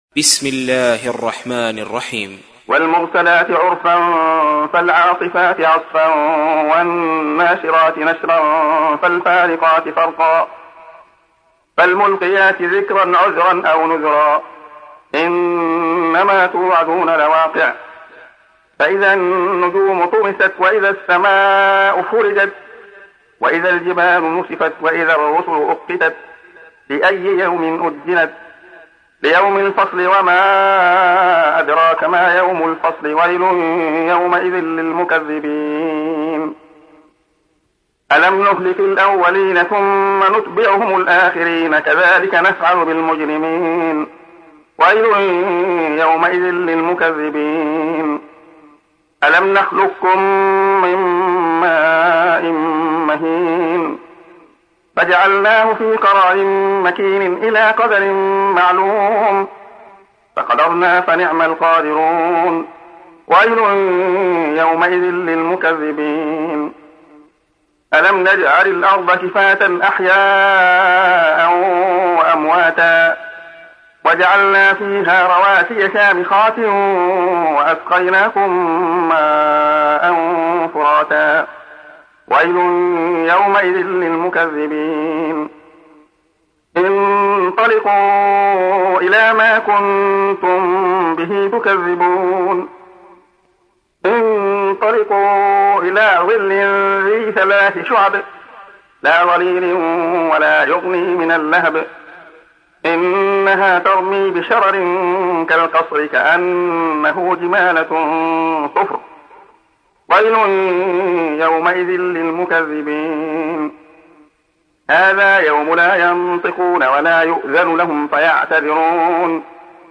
تحميل : 77. سورة المرسلات / القارئ عبد الله خياط / القرآن الكريم / موقع يا حسين